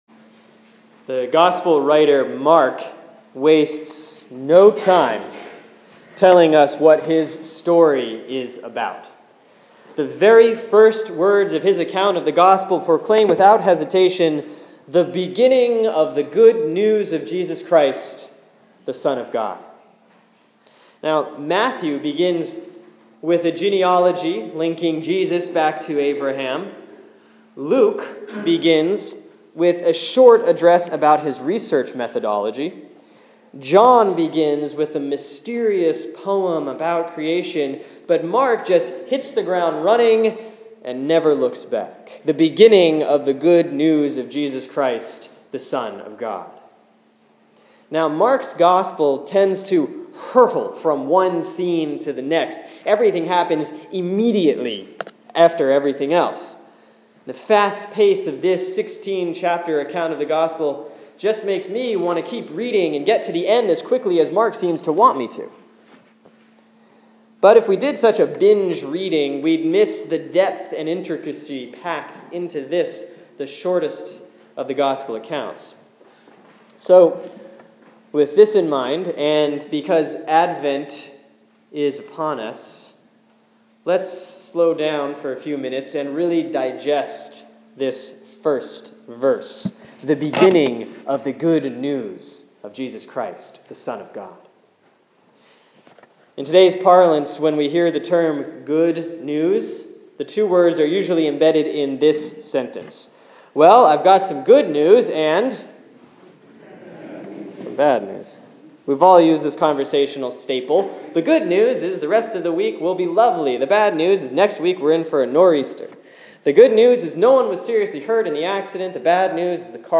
Sermon for Sunday, December 7, 2014 || Advent 2B || Mark 1:1-8